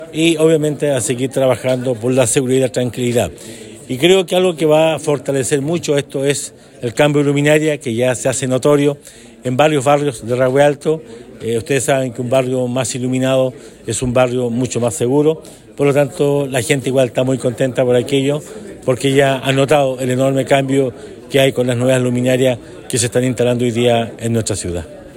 El jefe comunal agregó que medidas como el recambio de luminarias y la recuperación de espacios públicos, permite que se avance en estrategias de seguridad que sean efectivas para la comunidad.